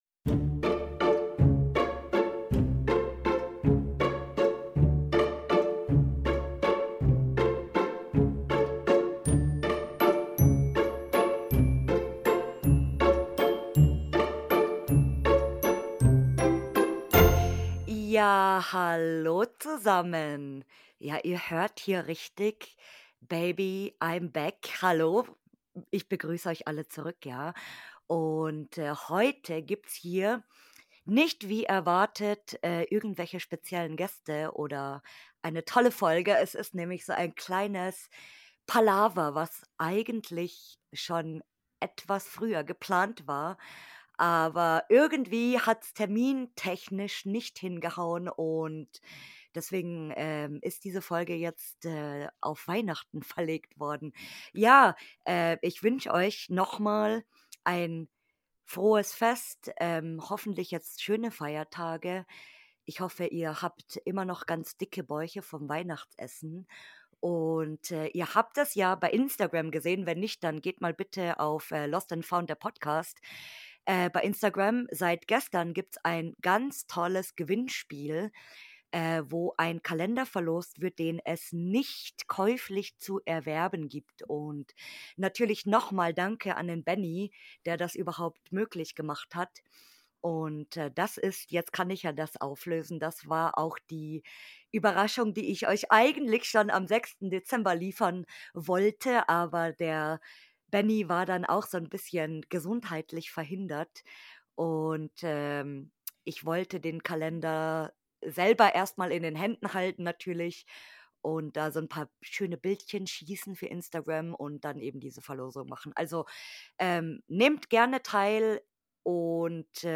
In dieser Folge hört ihr ein kleines Palaver